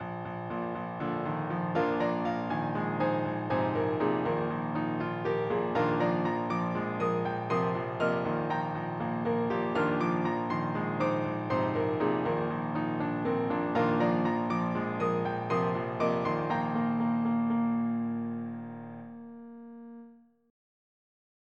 MIDI Composition
They are short and experimental.